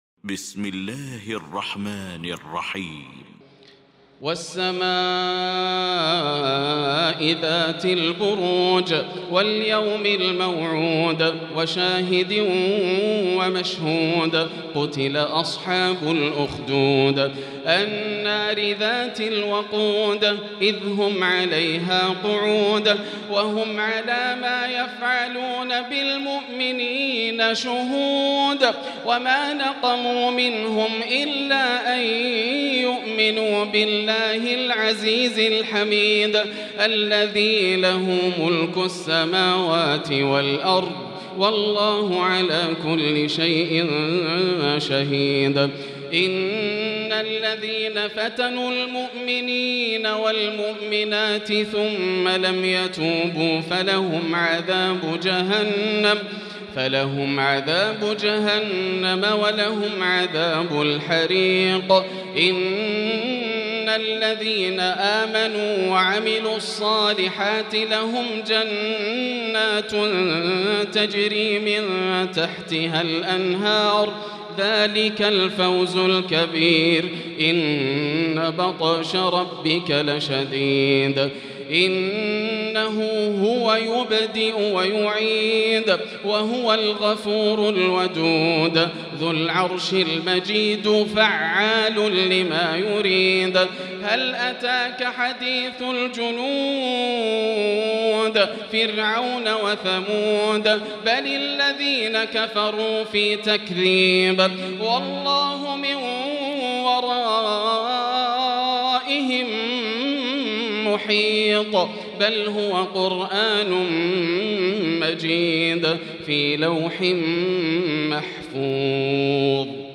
المكان: المسجد الحرام الشيخ: فضيلة الشيخ ياسر الدوسري فضيلة الشيخ ياسر الدوسري البروج